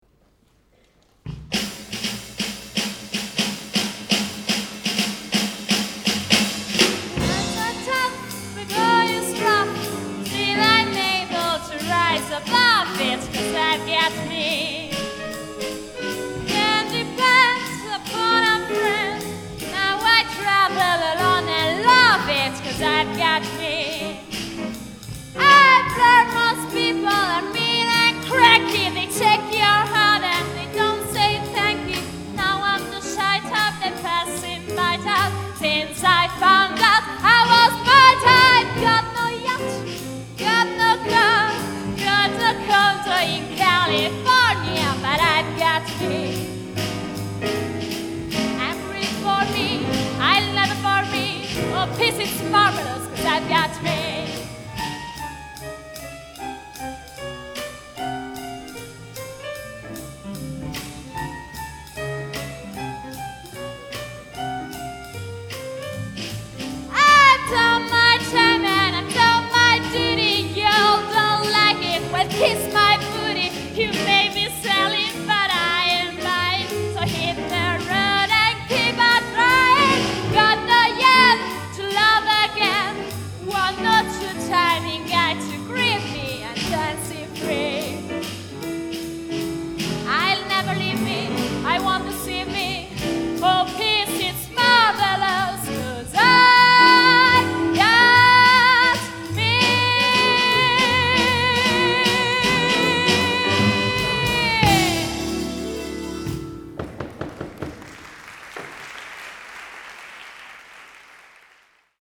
sax contralto
sax tenore
clarinetto
tromba
trombone
chitarra elettrica
pianoforte
basso elettrico
batteria
GenereJazz